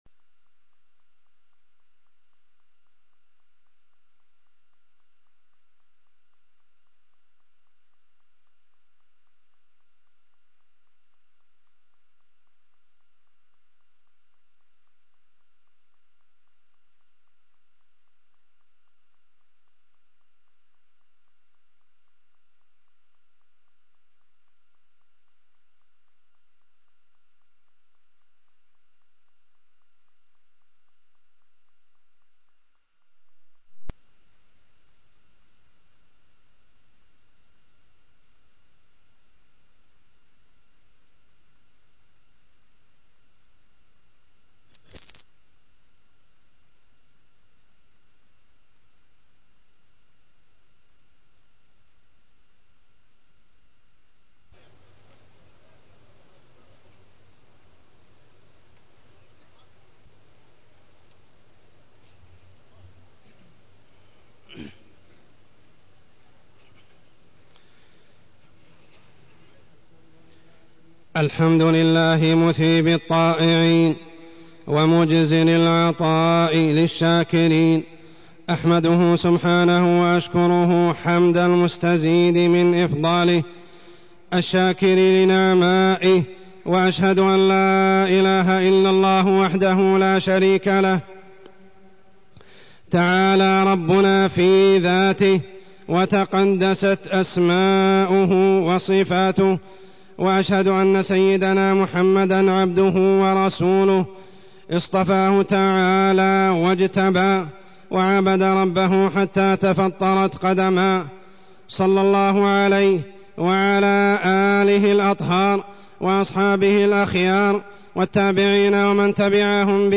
تاريخ النشر ٢٢ رمضان ١٤٢٢ هـ المكان: المسجد الحرام الشيخ: عمر السبيل عمر السبيل العشر الأواخر من رمضان